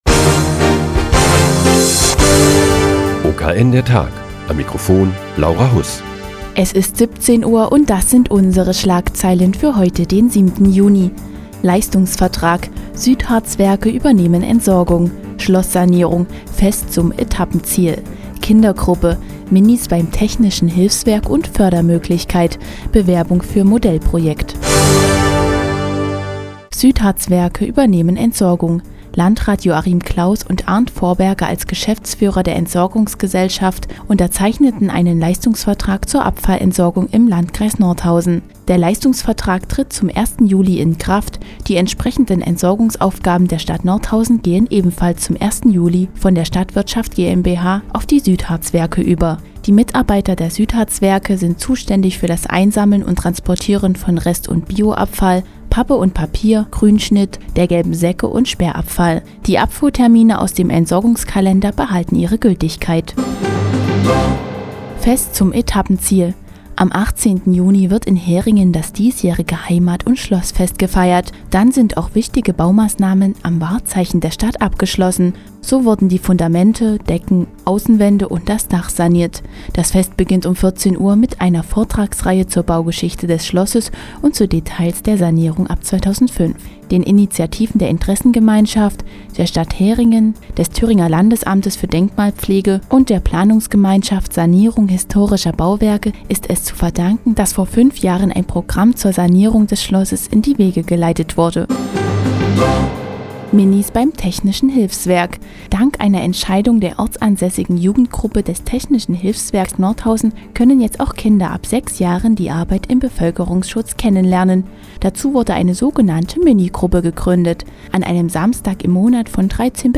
Die tägliche Nachrichtensendung des OKN ist nun auch in der nnz zu hören. Heute geht es um die neu gegründete Mini- Gruppe beim Technischen Hilfswerk und die Bewerbung für das Modellprojekt "Bürgerarbeit".